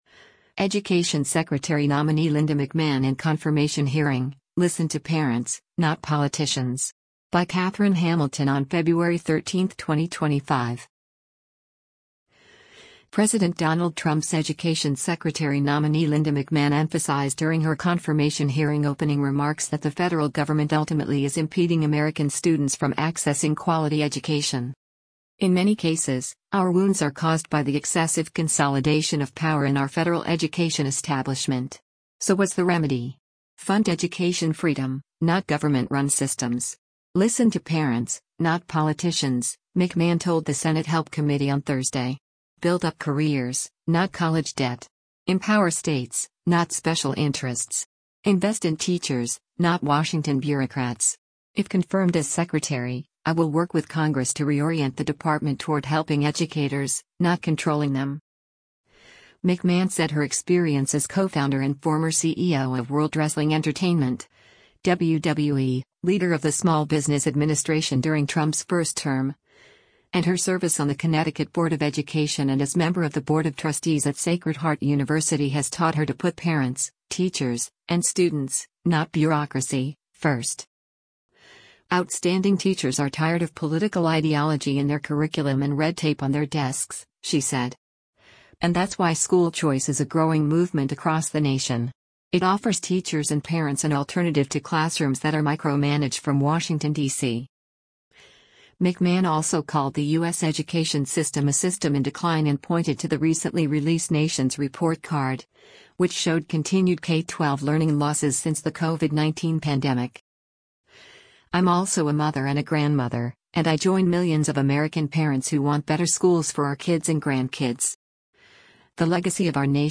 Education Secretary Nominee Linda McMahon in Confirmation Hearing: ‘Listen to Parents, Not Politicians’